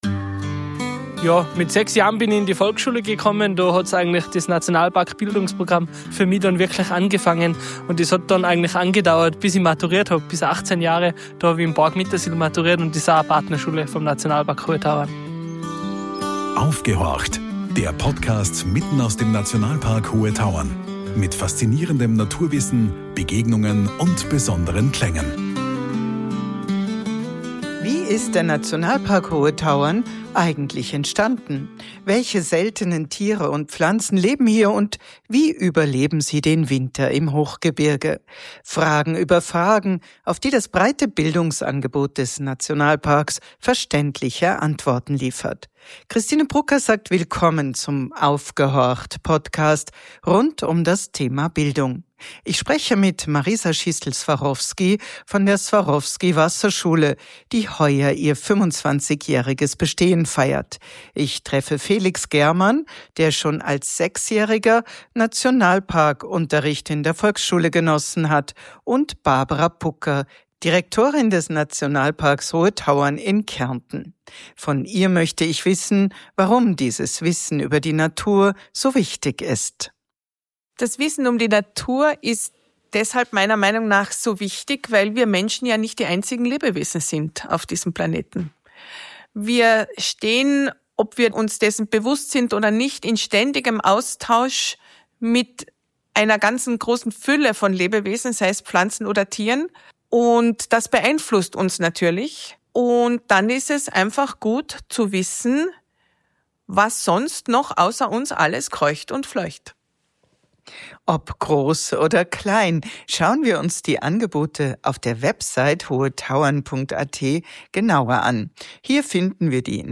#006 Die Natur als Lehrmeisterin ~ Aufgehorcht! Der Podcast mitten aus dem Nationalpark Hohe Tauern.